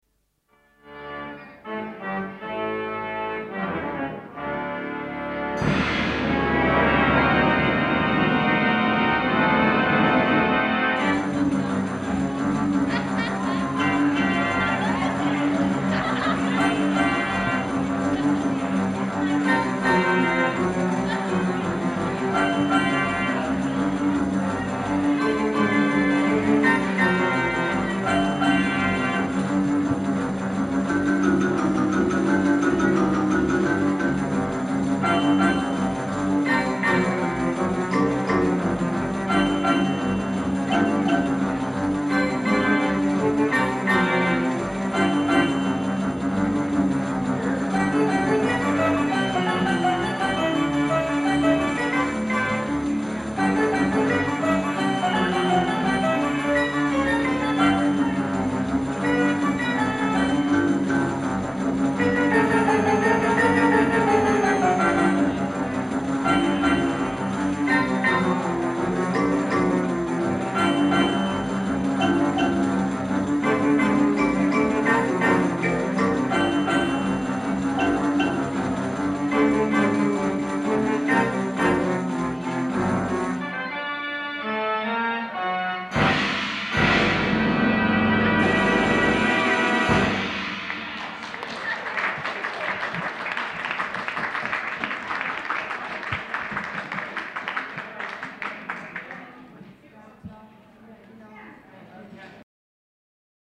In the archives we find an original tape recorded circa 1970 in California on a UHER portable machine. (4000 report-L, 7.5 “/sec, mono, half track) – Pizza & Pipes at the “Cap’ns Galley”.
web pics of the Uher recorder used in the late 1960’s for recording the Cap’ns Galley organ.